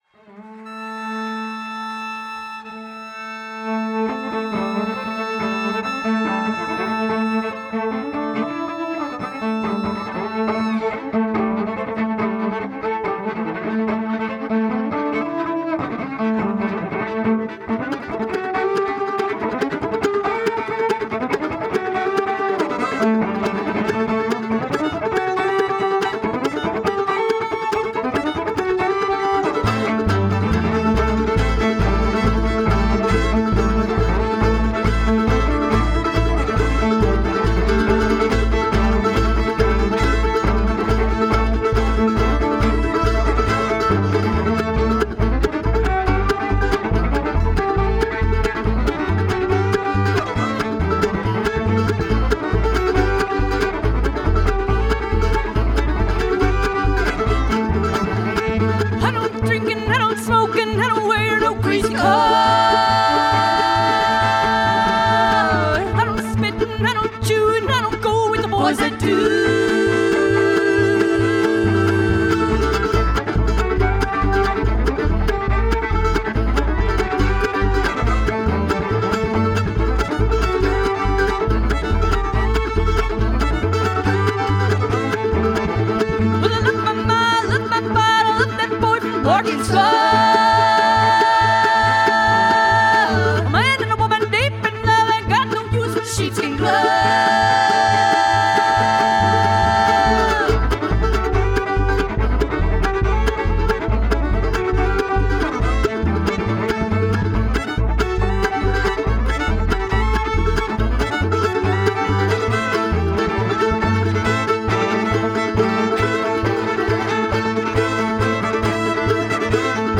Double Bass, Guitar, Vocals
Mandolin, Guitar, Vocals
Fiddle, Vocals
Cello, Vocals